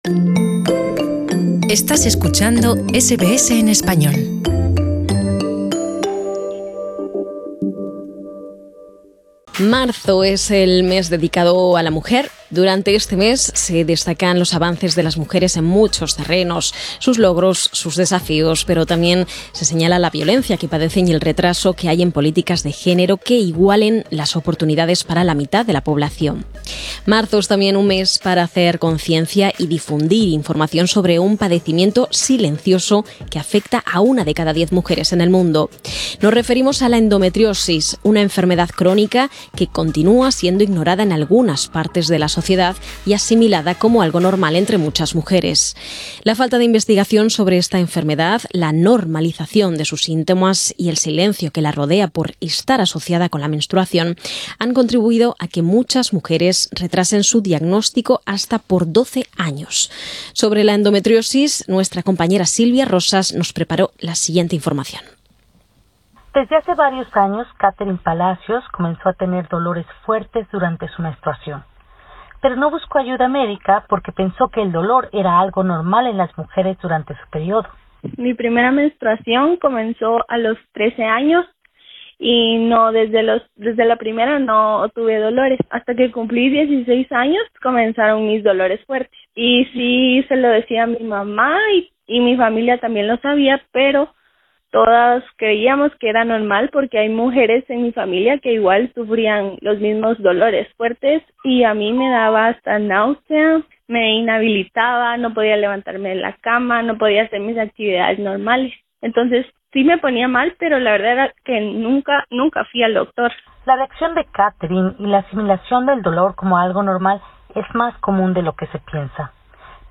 Para saber más sobre la endometriosis escucha este reporte.